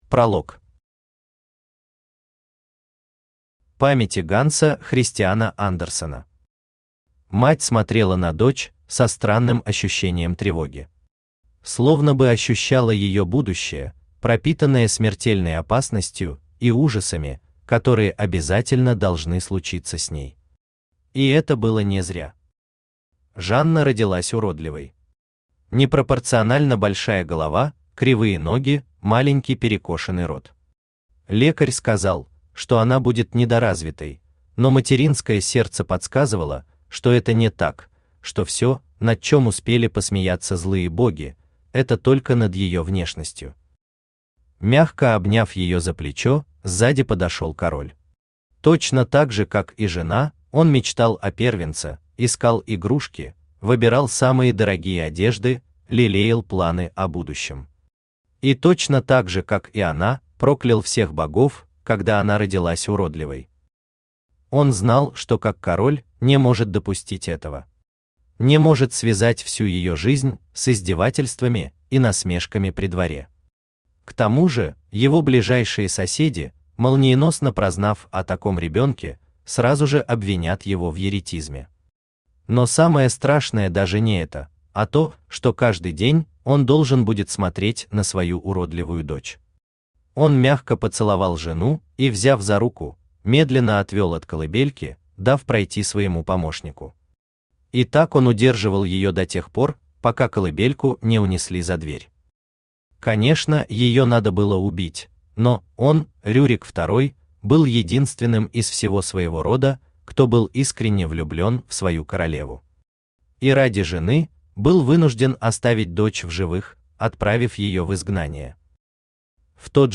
Aудиокнига Башня Автор Даниил Заврин Читает аудиокнигу Авточтец ЛитРес.